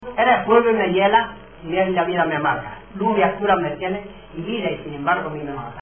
Materia / geográfico / evento: Canciones de amor Icono con lupa
Santa Cruz del Comercio (Granada) Icono con lupa
Secciones - Biblioteca de Voces - Cultura oral